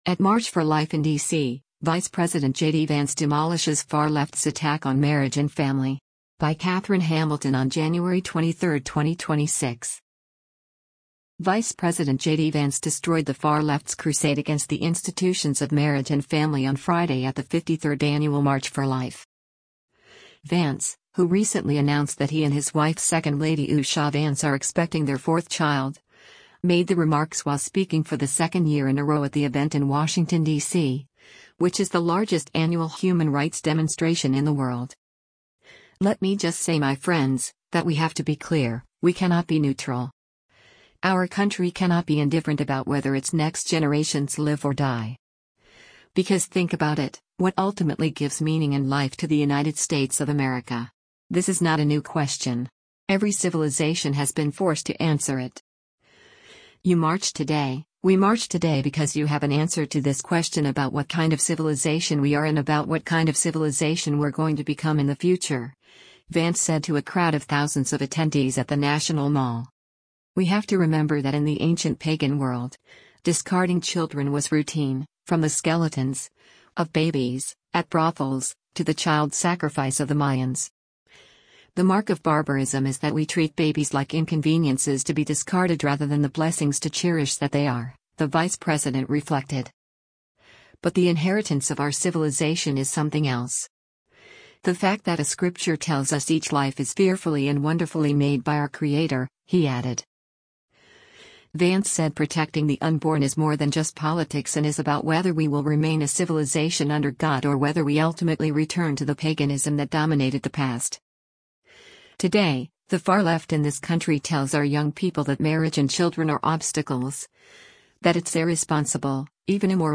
At March for Life in DC, Vice President JD Vance Demolishes Far Left’s Attack on Marriage and Family
Vance, who recently announced that he and his wife second lady Usha Vance are expecting their fourth child, made the remarks while speaking for the second year in a row at the event in Washington, DC, which is the largest annual human rights demonstration in the world.